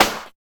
69 SNARE.wav